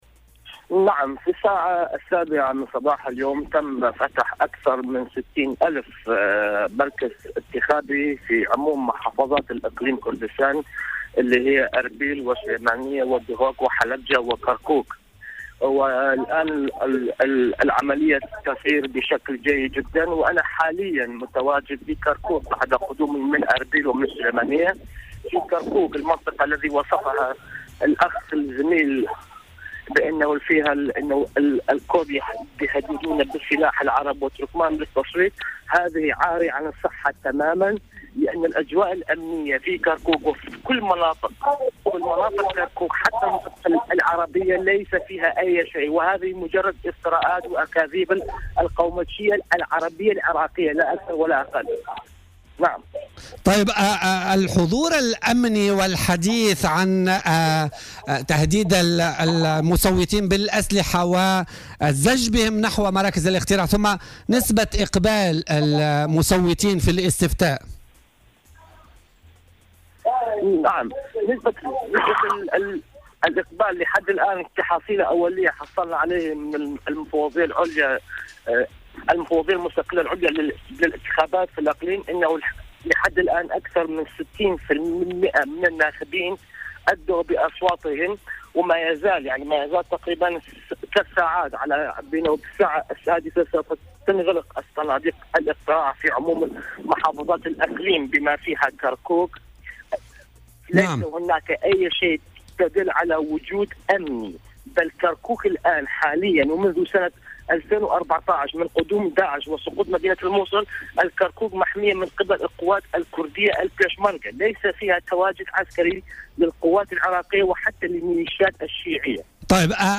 مداخلة هاتفية